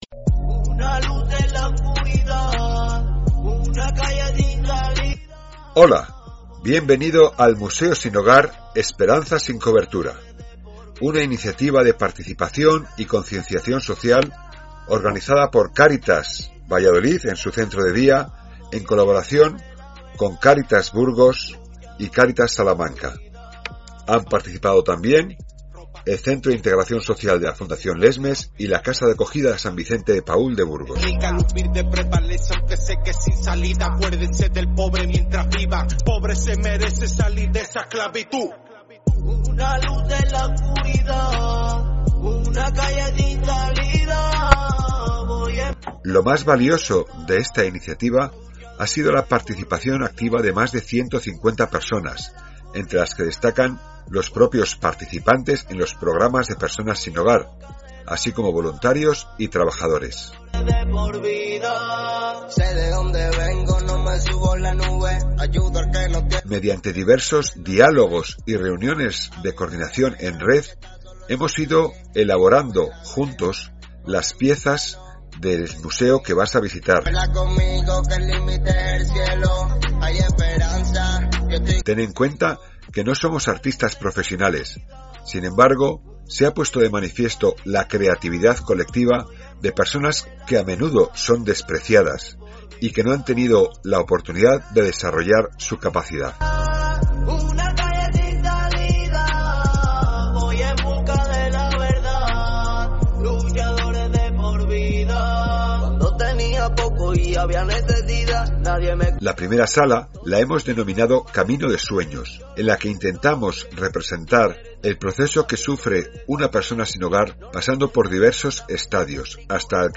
Audioguia_Museo-sin-Hogar.mp3